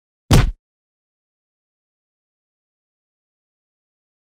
赤手空拳击中肉体13-YS070524.mp3
通用动作/01人物/03武术动作类/空拳打斗/赤手空拳击中肉体13-YS070524.mp3
• 声道 立體聲 (2ch)